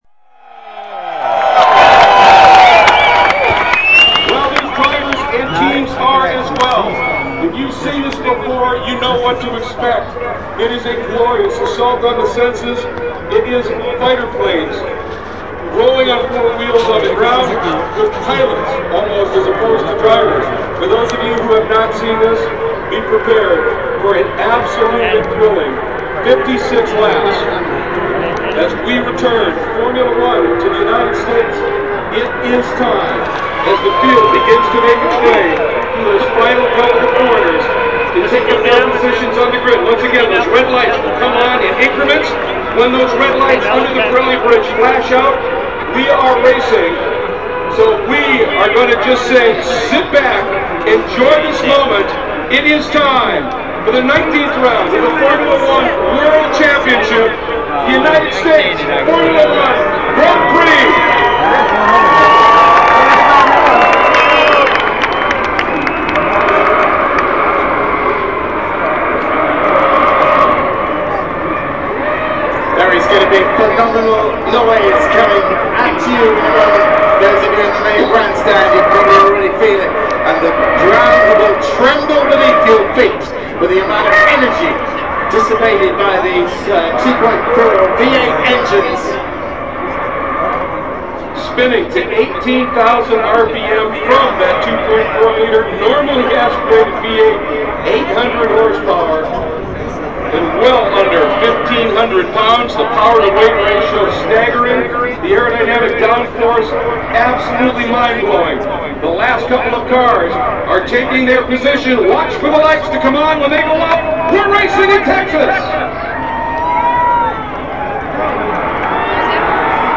When everyone was in place the race started with a ROAR.
The noise from the engines and down shifting was incredible.